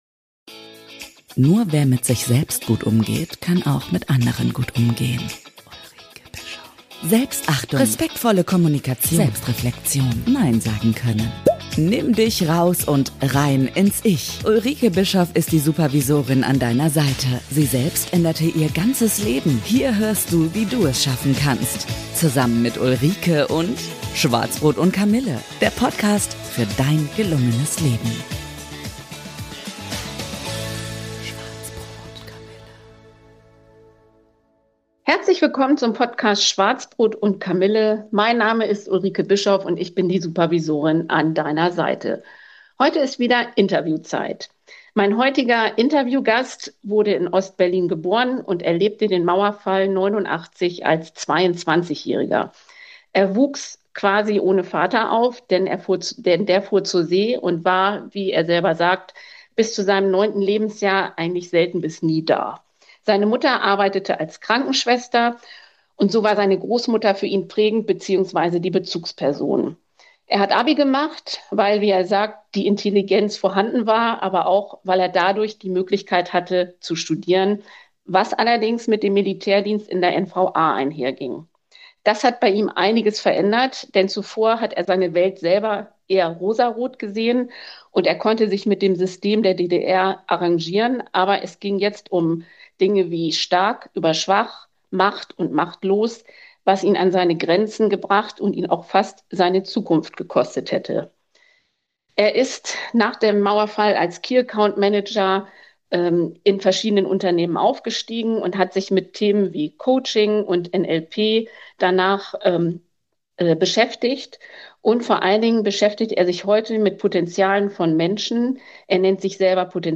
Im Gespräch geht es um Lebensphasen, in denen wir uns neu erfinden, um das Vertrauen in die eigene Intuition und darum, warum es manchmal einfach wichtig ist, loszugehen – auch wenn der Weg noch nicht klar ist.